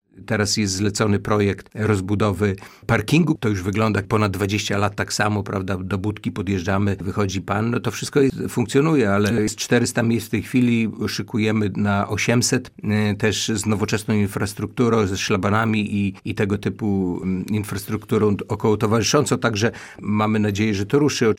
Od kilkunastu lat parking wygląda tak samo i najwyższy czas to zmienić – mówi Jacek Piorunek, członek zarządu woj. podlaskiego